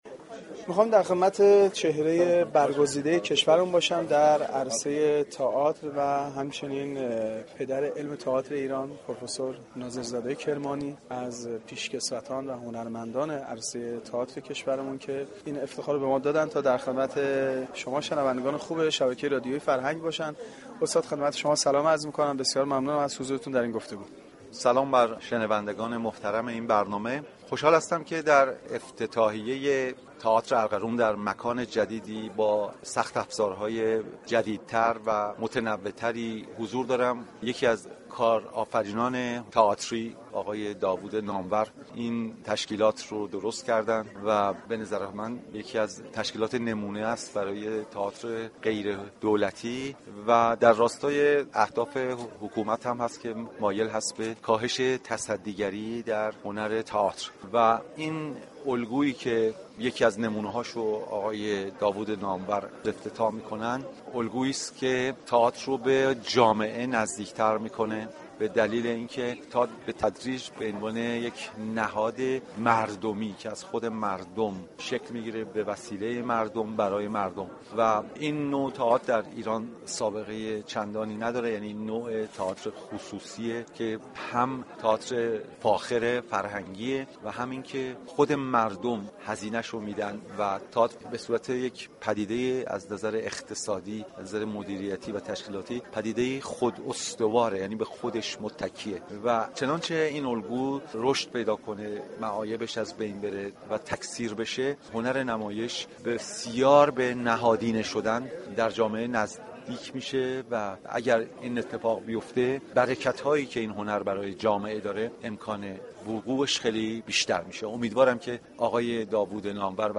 مراسم افتتاحیه عمارت نمایشی ارغنون همزمان با برگزاری جشن هفتادمین سالروز تولد